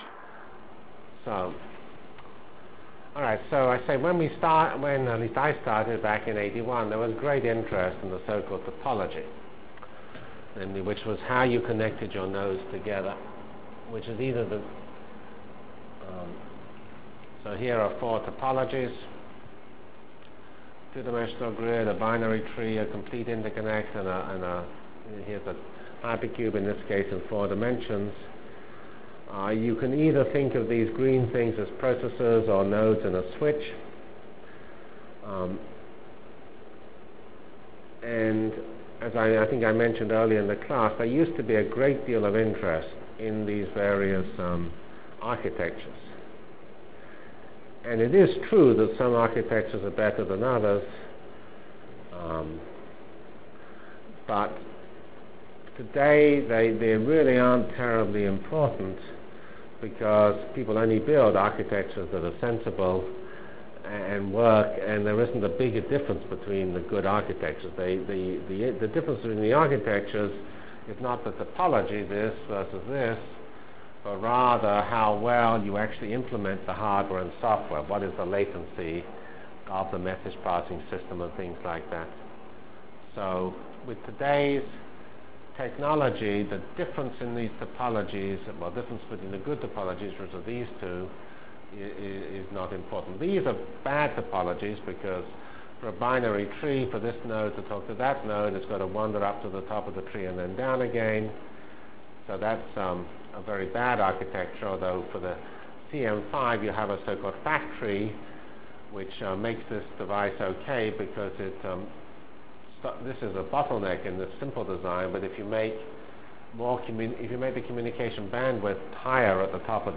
Delivered Lectures of CPS615 Basic Simulation Track for Computational Science -- 12 September 96.